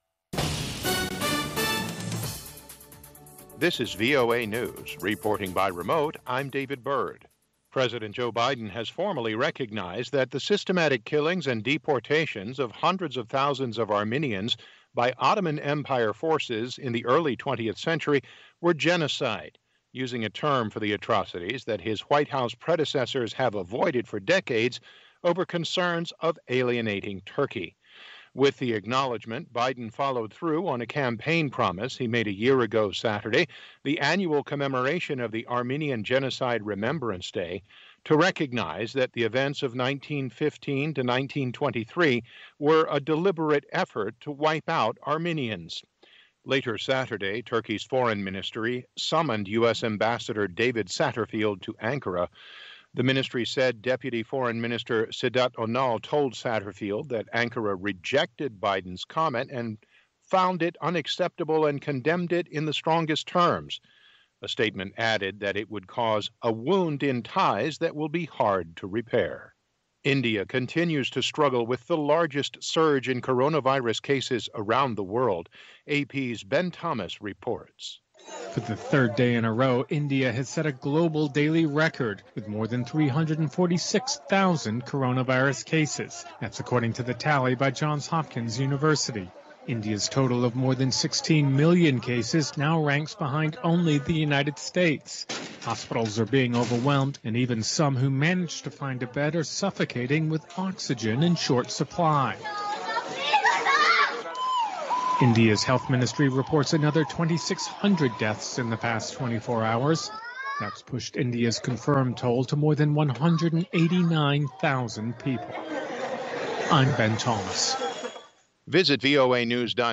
VOA Newscastの4/25放送分です。